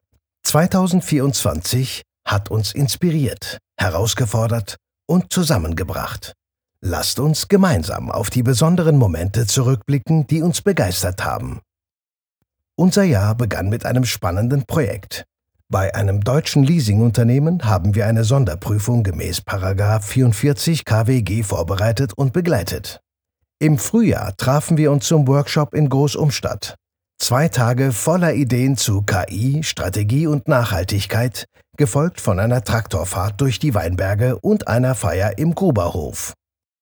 Male
Adult (30-50)
Corporate